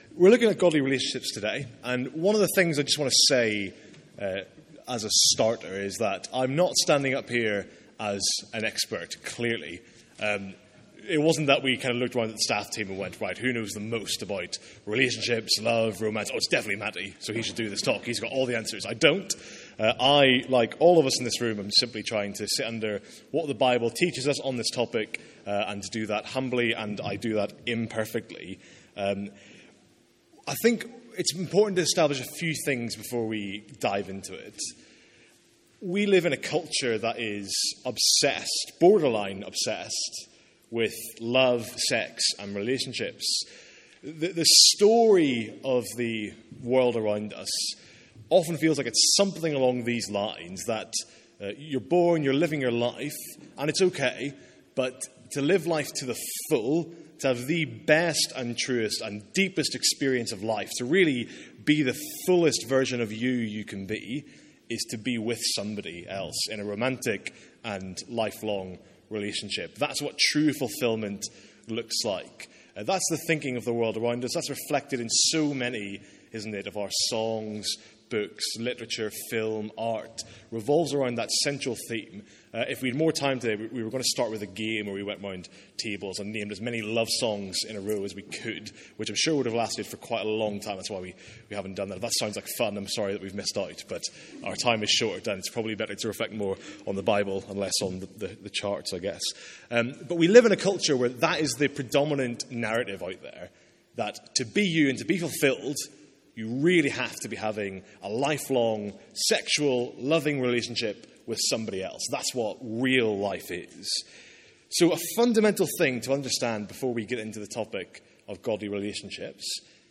From our second student lunch of the academic year.